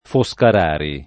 [ fo S kar # ri ]